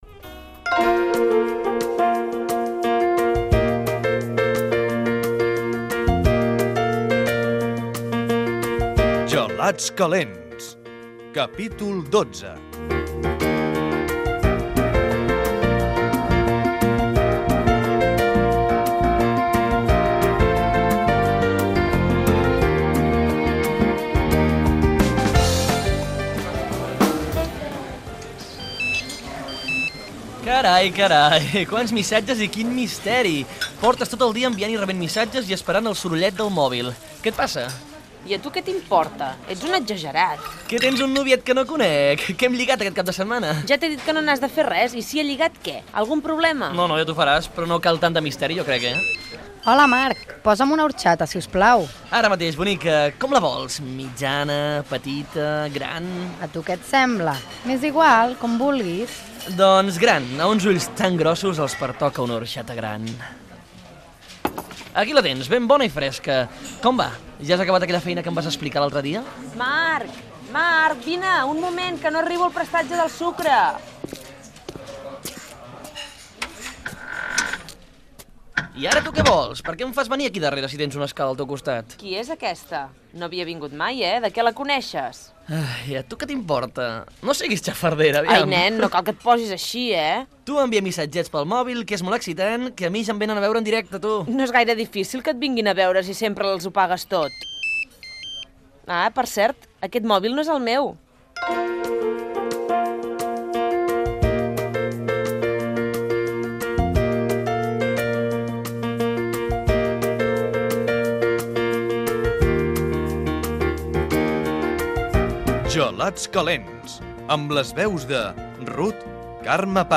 Serial "Gelats calents". Diàleg entre els personatges al bar. El cambrer s'interessa per una clienta que hi entra. Careta amb el repartiment
Ficció